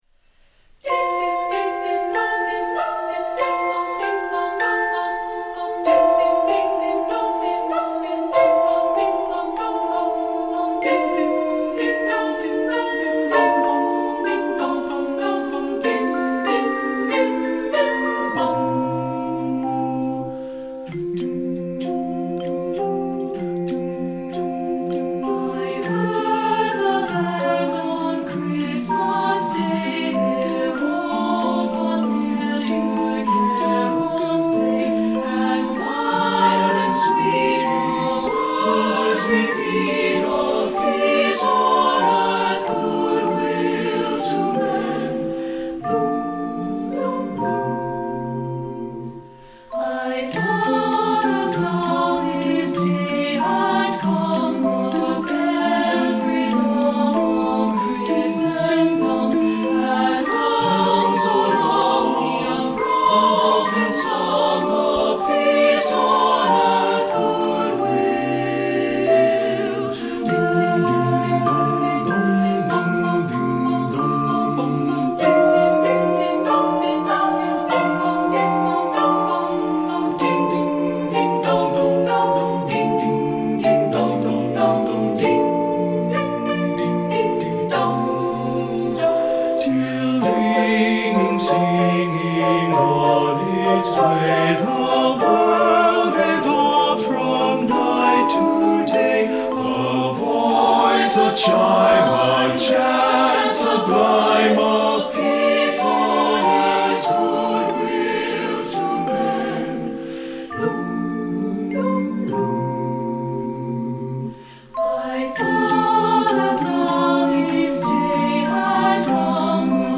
Choral Christmas/Hanukkah
SATB